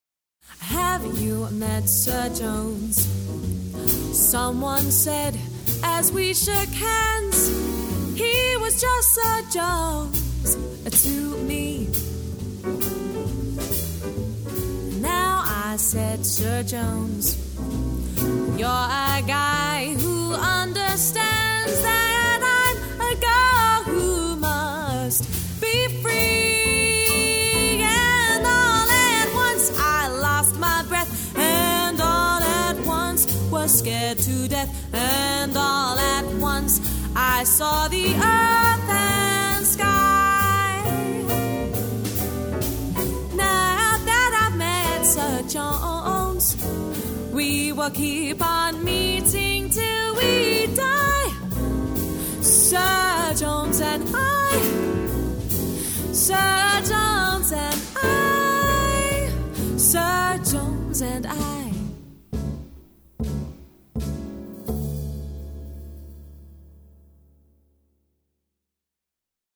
Live Jazz Quartet
classic Swing from the 1930s and 1940s
sultry female vocals